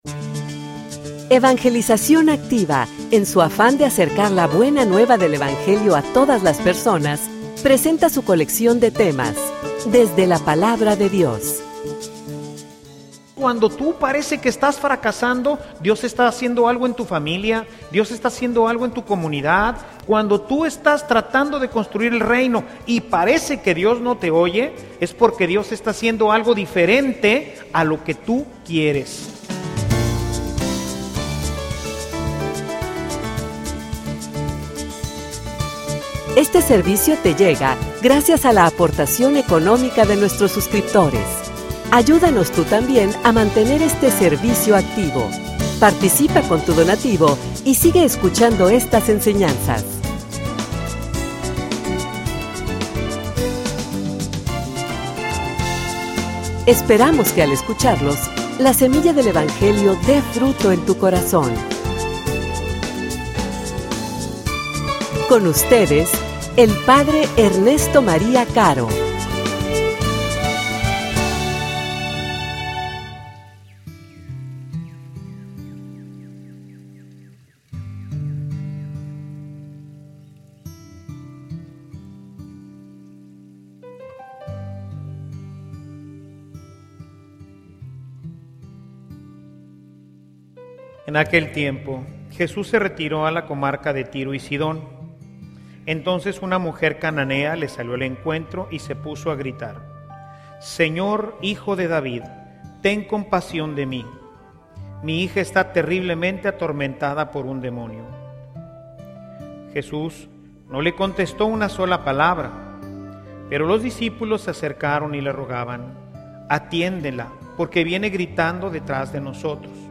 homilia_Vamos_por_el_Si.mp3